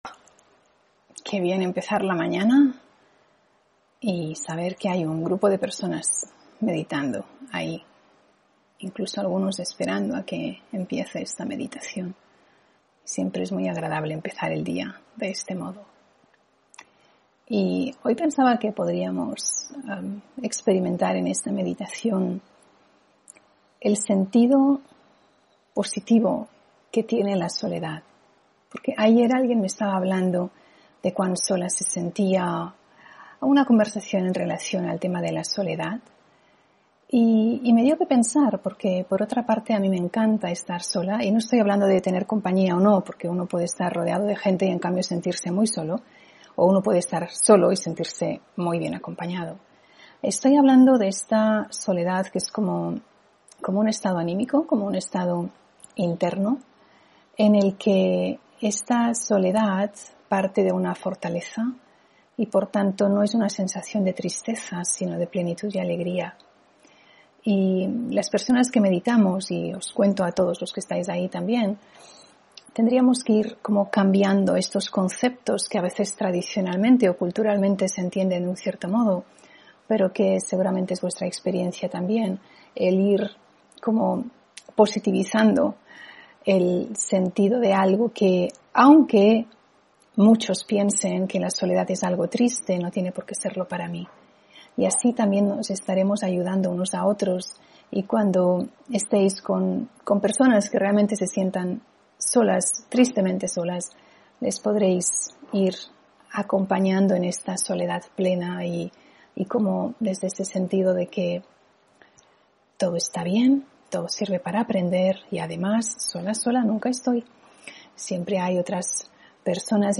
Meditación de la mañana: Soledad plena